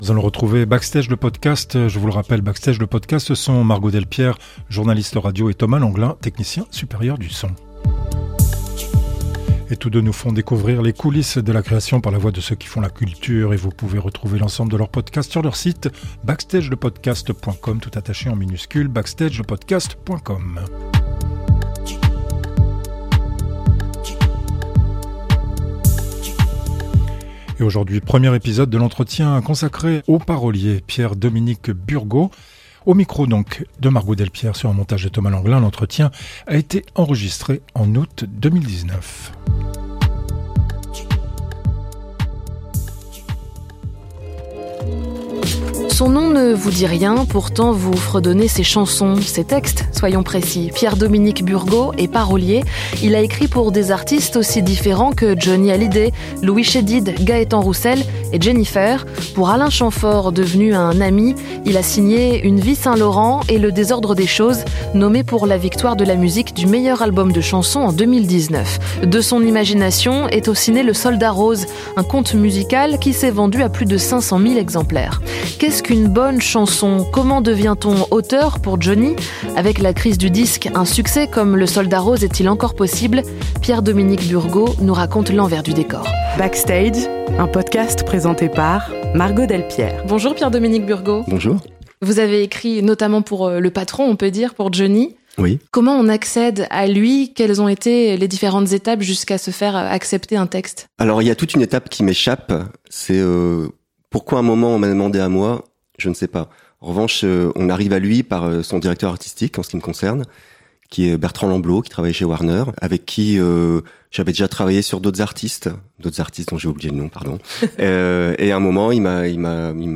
Entretien enregistré en août 2019.